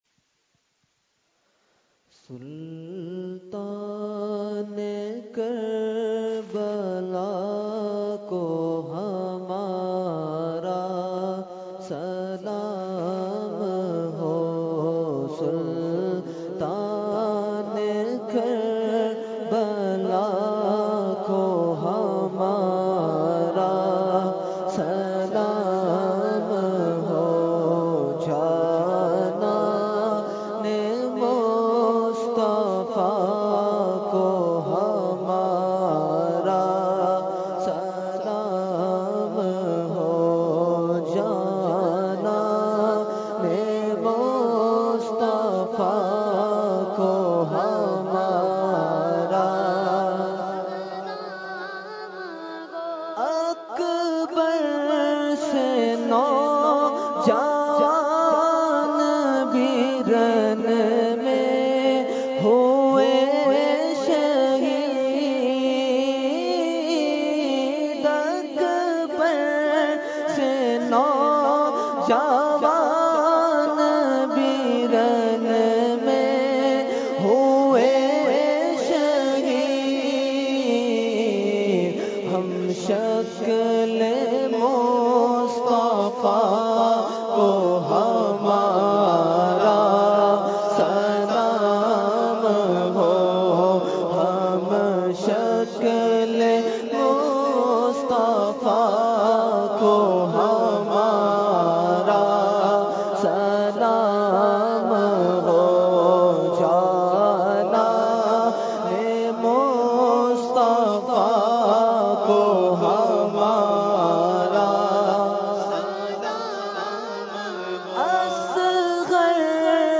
Category : Salam | Language : UrduEvent : Muharram 2017